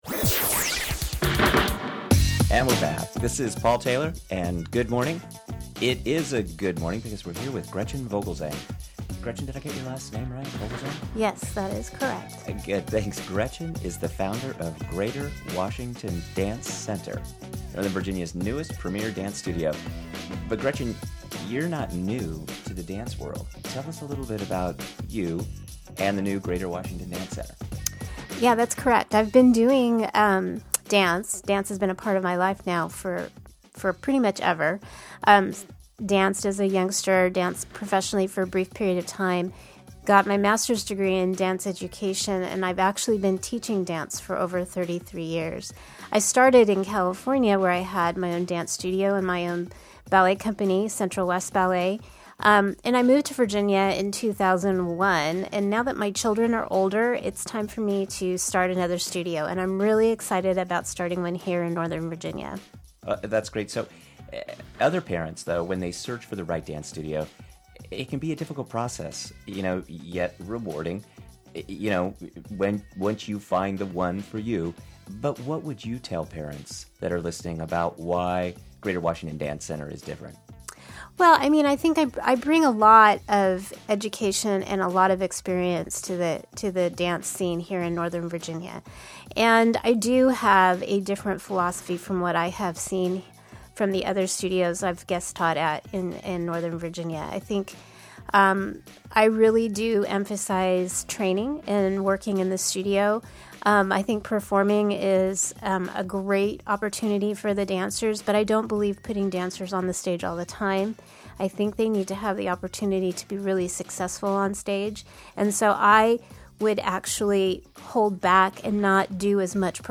Interview with Founder